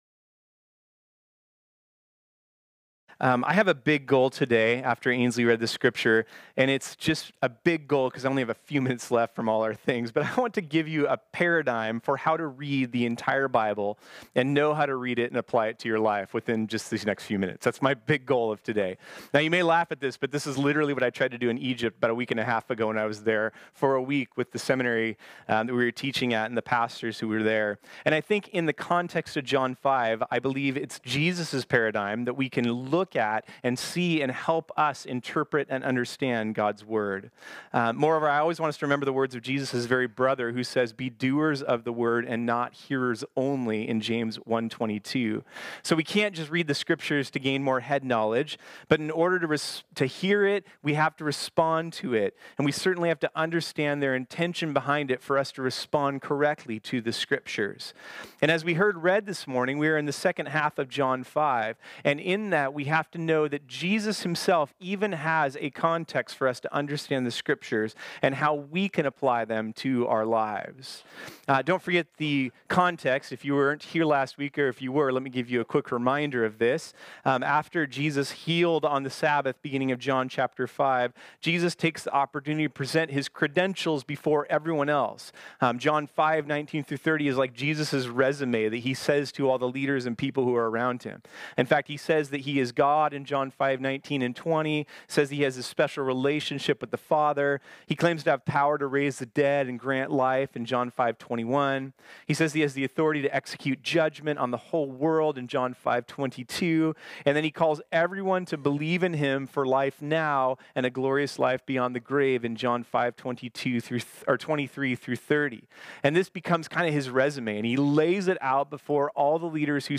This sermon was originally preached on Sunday, November 17, 2019.